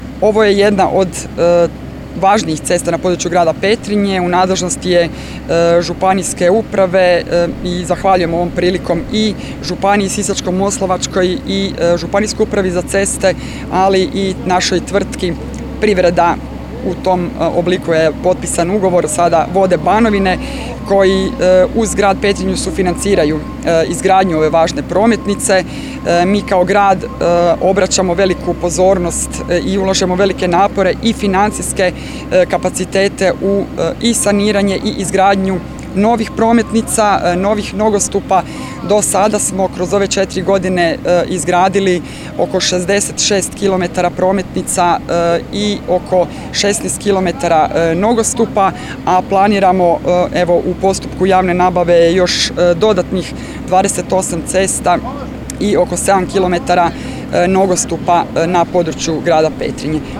Zadovoljstvo radovima iskazala je i gradonačelnica Grada Petrinje Magdalena Komes